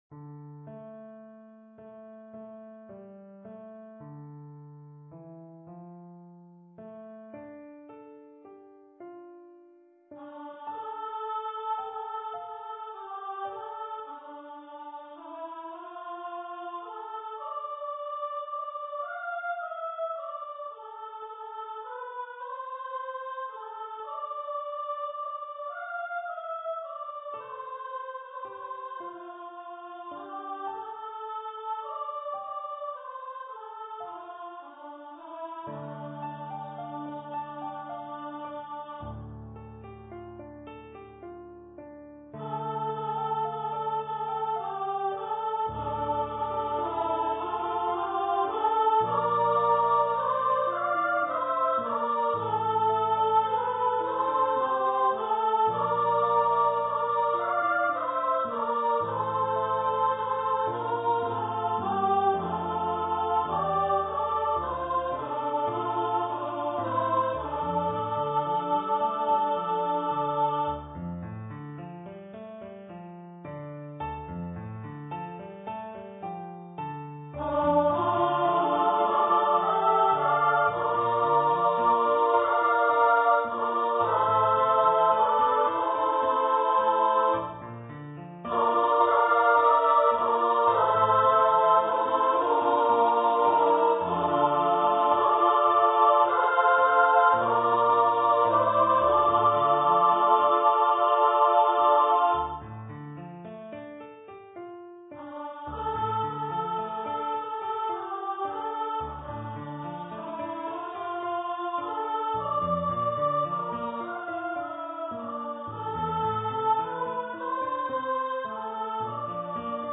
for female voice choir
for SSAA choir and piano
traditional Newfoundland song.
Choir - 4 part upper voices